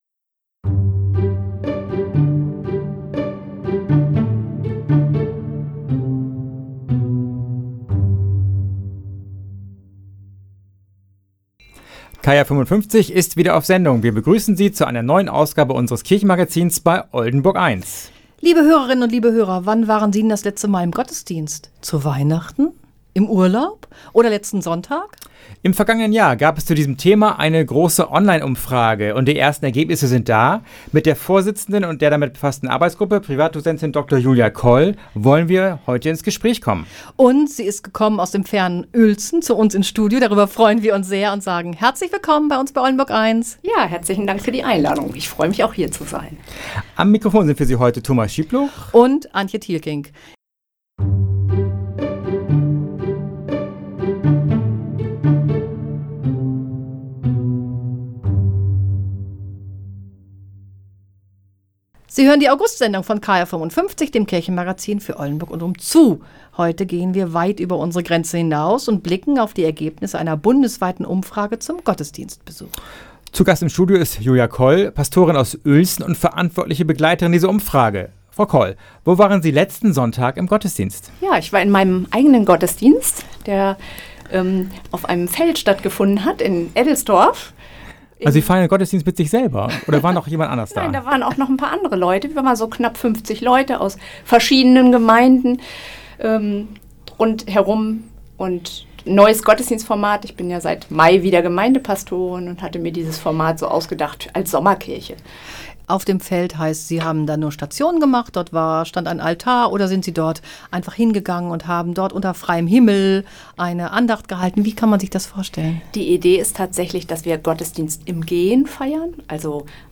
Geistlicher Snack